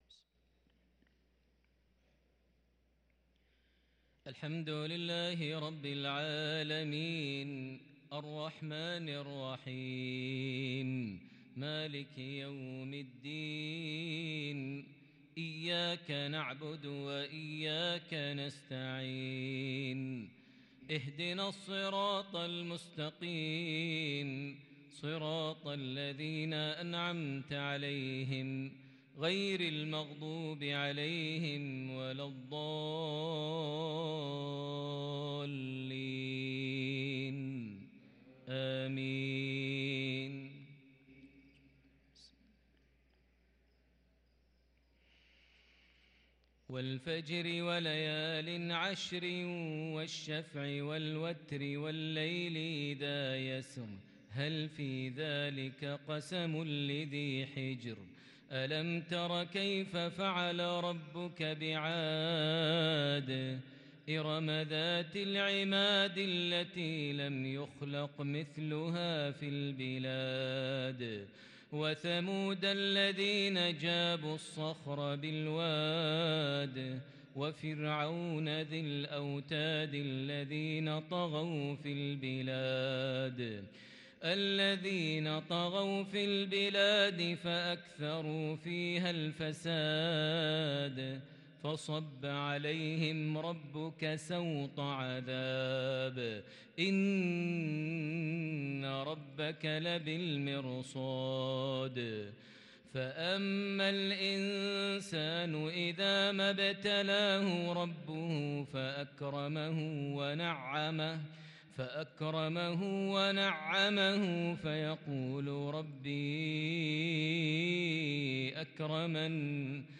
صلاة المغرب للقارئ ماهر المعيقلي 29 جمادي الأول 1444 هـ
تِلَاوَات الْحَرَمَيْن .